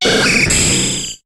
Cri d'Airmure dans Pokémon HOME.